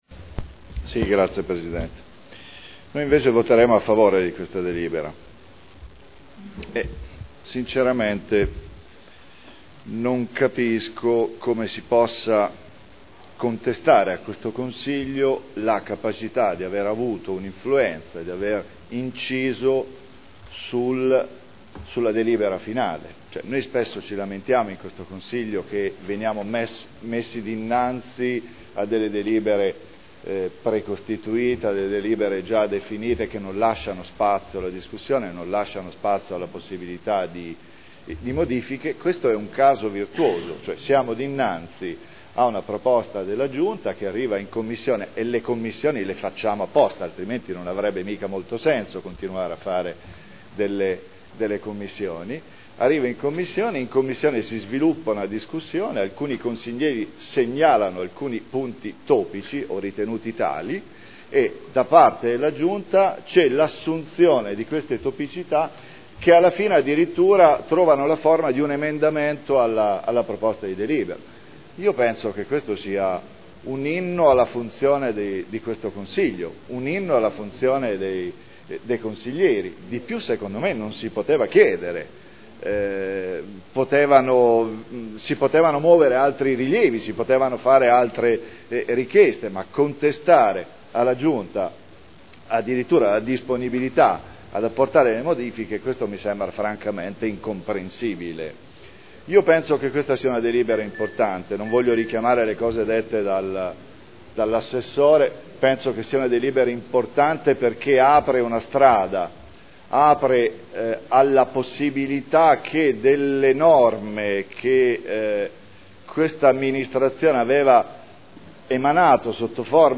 Paolo Trande — Sito Audio Consiglio Comunale
Seduta del 20/06/2011. Modifiche al Regolamento di Polizia Urbana approvato con deliberazione del Consiglio comunale n. 13 dell’11.2.2002 Dichiarazioni di voto